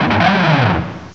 cry_not_krokorok.aif